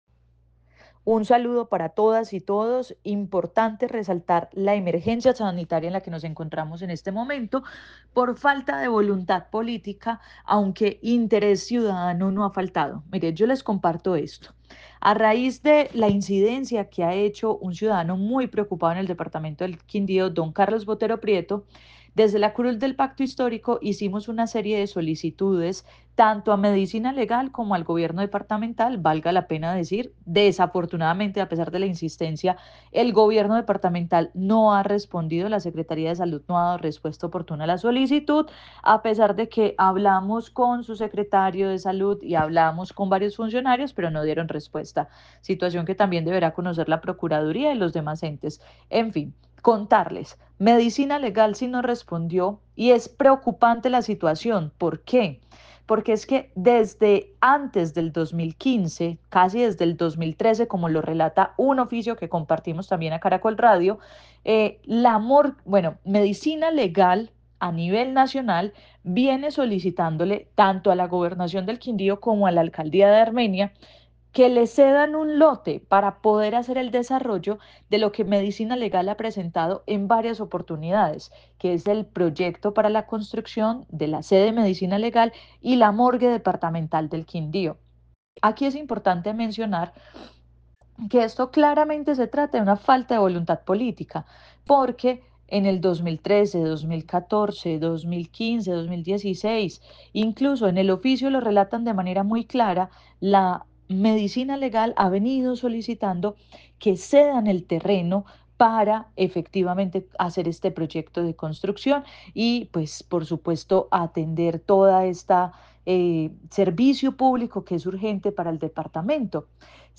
Diputada Jessica Obando